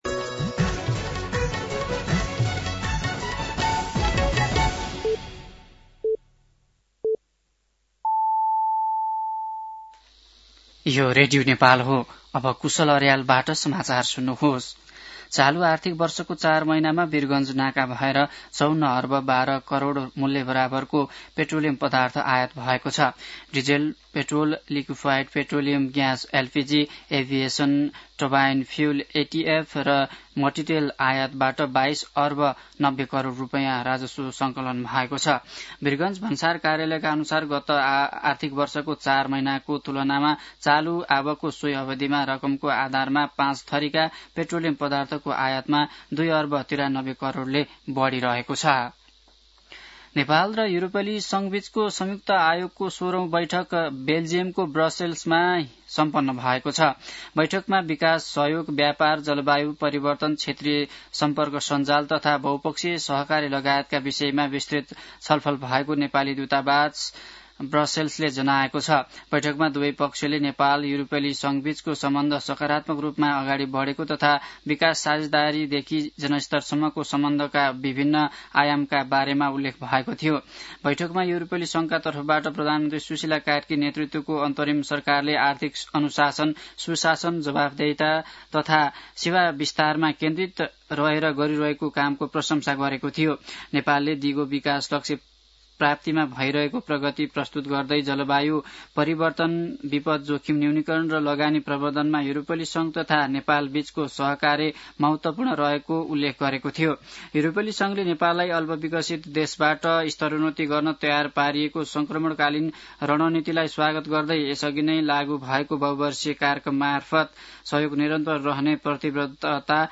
An online outlet of Nepal's national radio broadcaster
मध्यान्ह १२ बजेको नेपाली समाचार : १३ मंसिर , २०८२